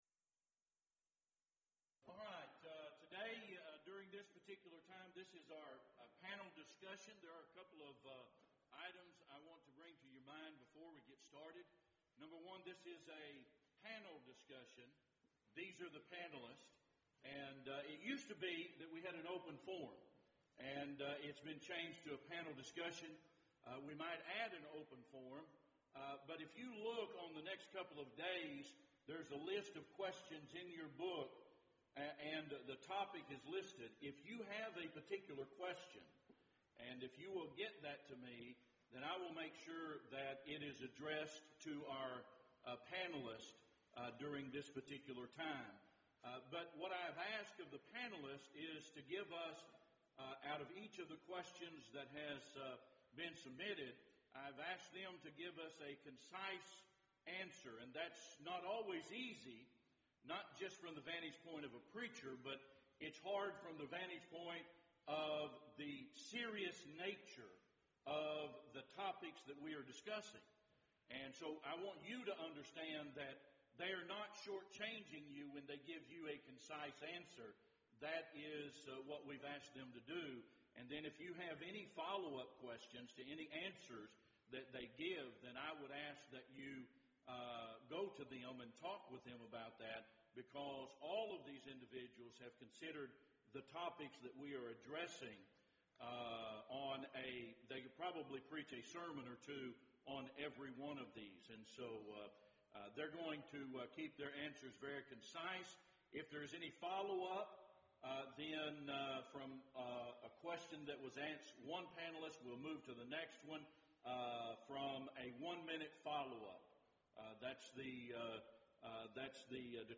Event: 4th Annual Men's Development Conference
lecture